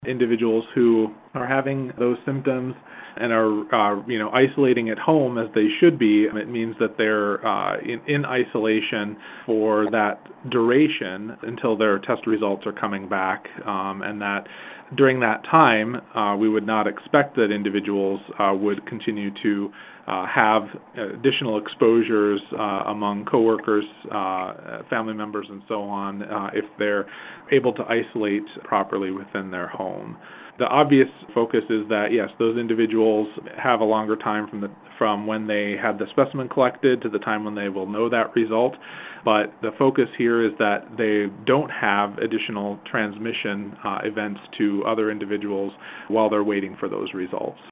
State epidemiologist Dr. Josh Clayton says while people are waiting for their COVID-19 test results, they should be self isolating.